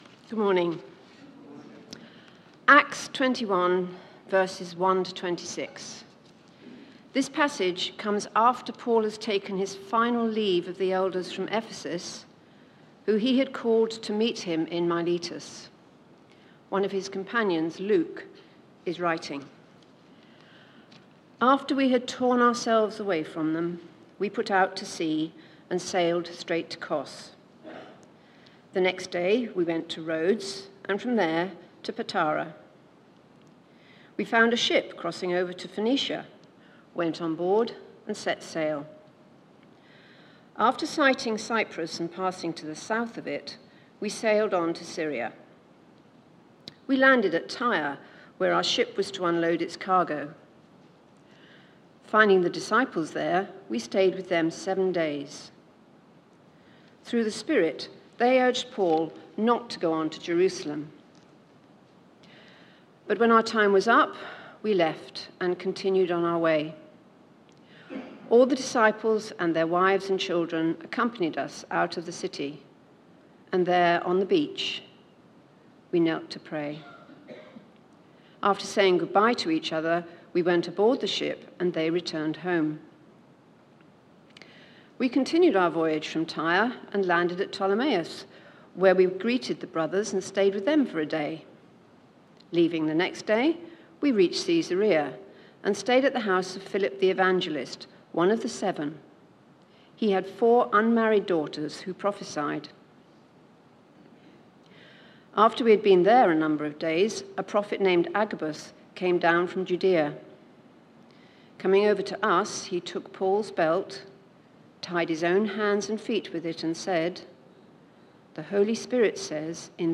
Media for Sunday Service on Sun 27th Oct 2024 10:00
Passage: Acts 21:1-26 Series: Book of Acts Theme: Sermon